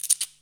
shaker6.wav